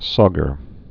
(sôgər)